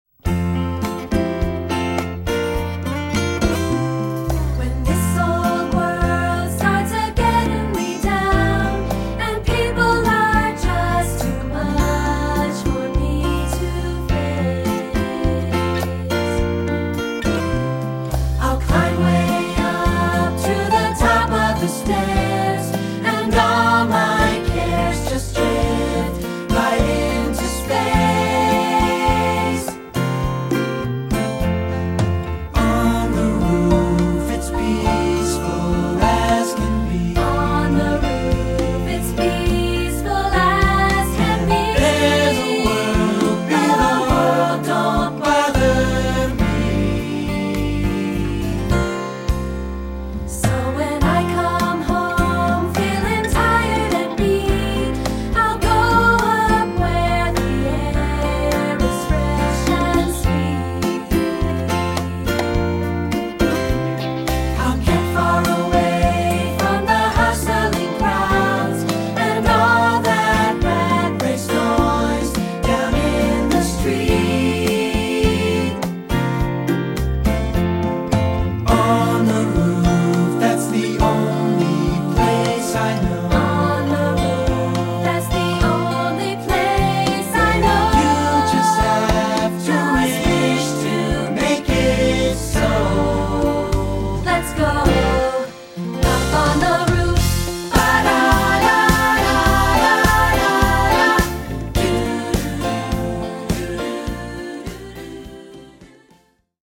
3 Part Mix
this arrangement is especially crafted for younger choirs.